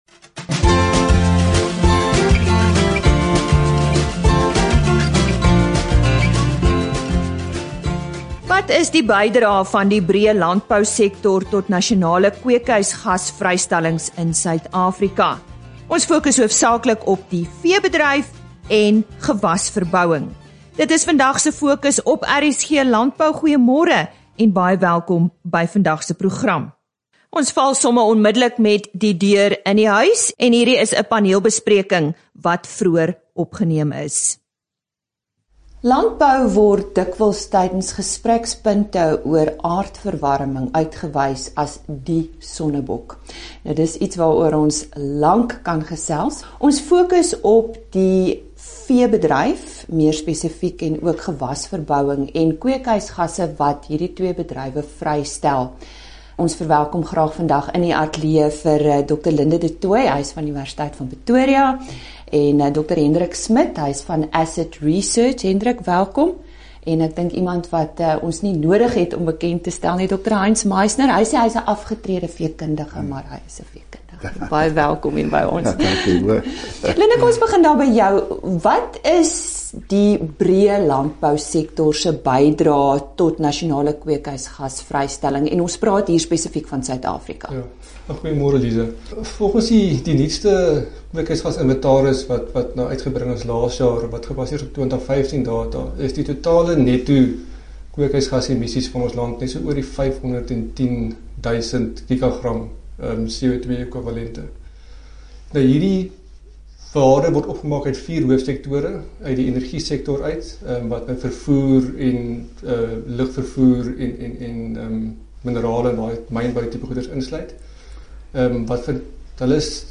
Sprekers wat deel vorm van dié paneelbespreking